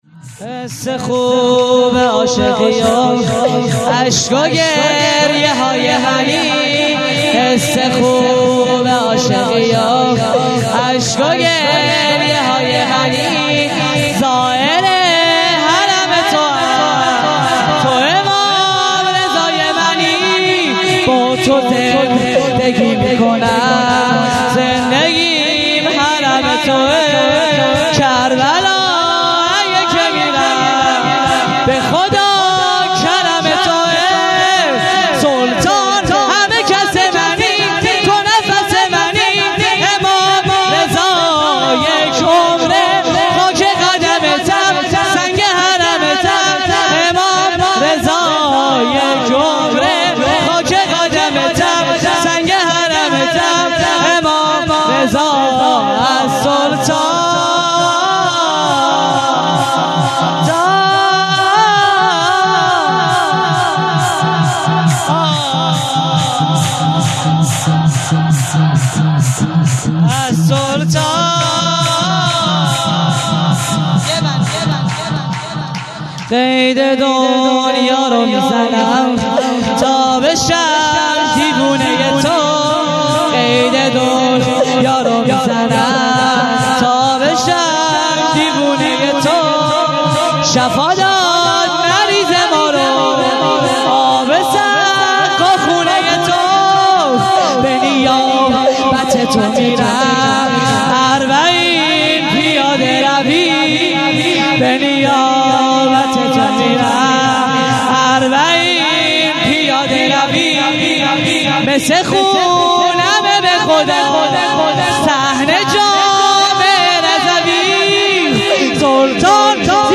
شور | حس خوب عاشقی